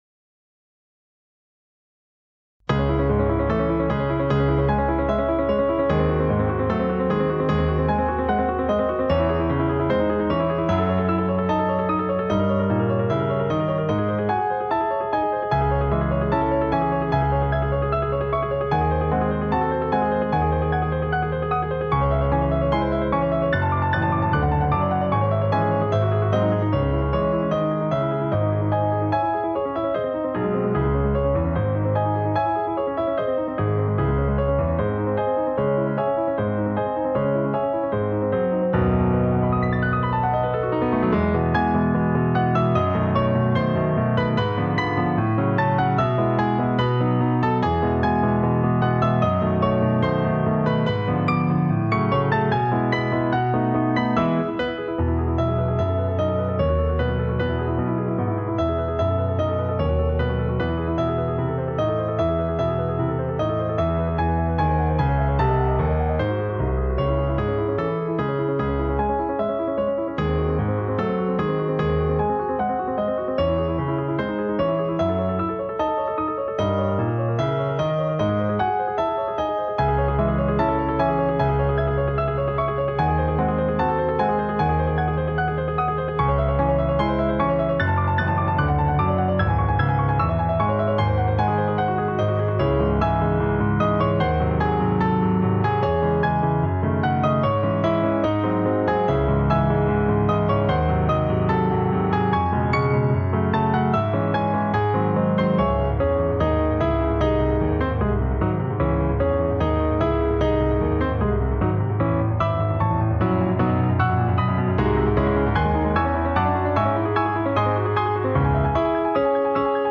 Allegro Agitato Grande Études de Salon Op.756 Czerny Download Audio mp3 MIDI File midi This work is licensed under a Creative Commons Attribution-NonCommercial-NoDerivatives 4.0 International License .